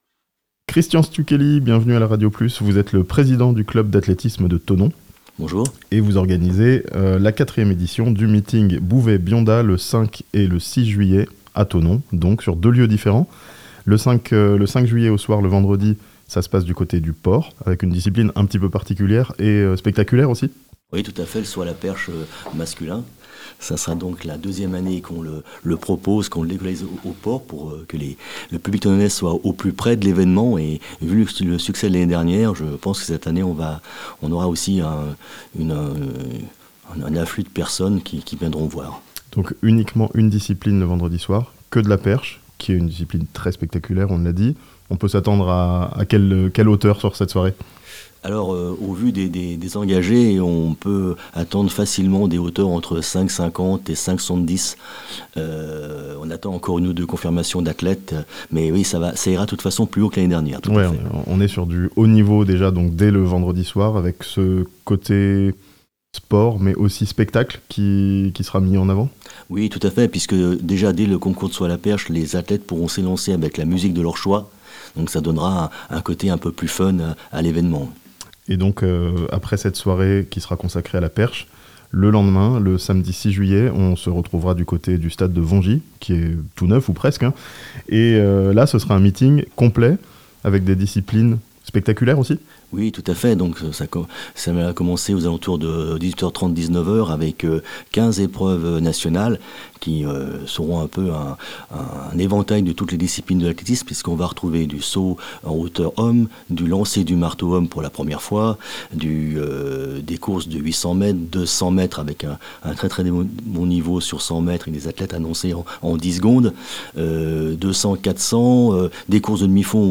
Un grand meeting d'athlétisme à Thonon ce weekend (interview)